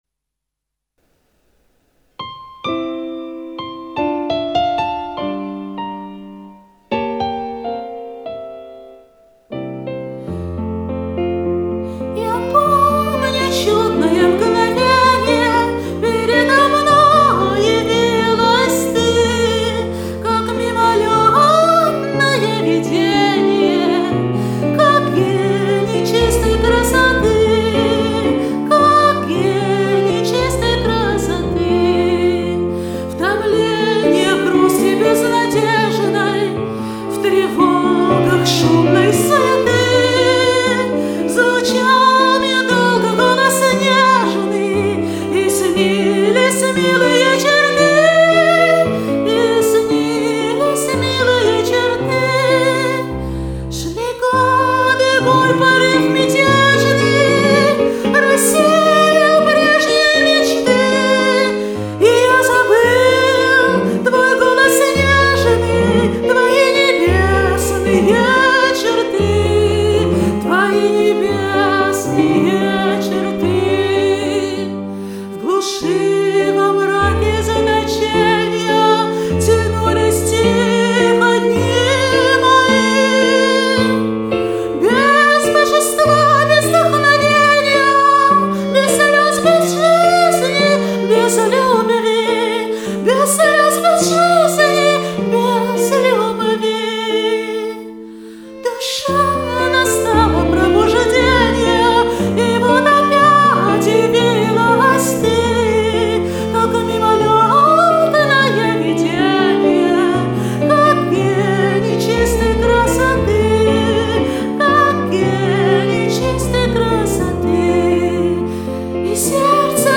Писклявый романс
романс Комментарий соперника
Первый и последний дубль. Пропищала :)